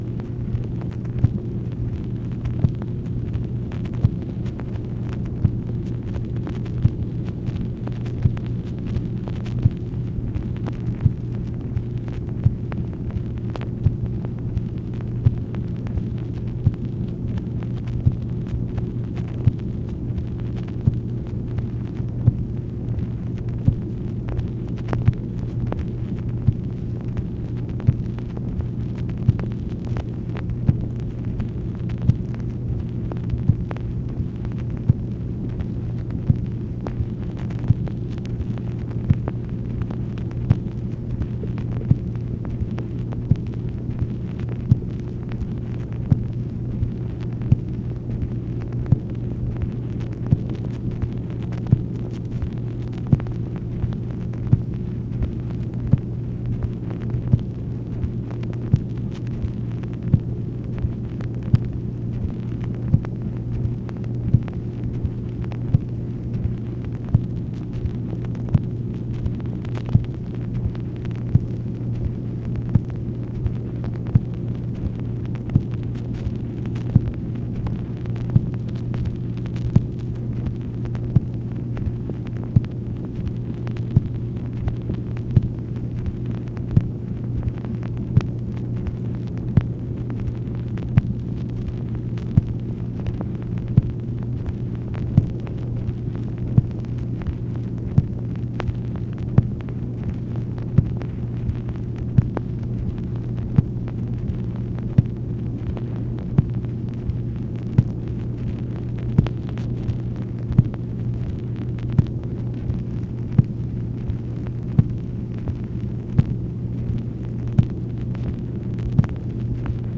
MACHINE NOISE, March 2, 1966
Secret White House Tapes | Lyndon B. Johnson Presidency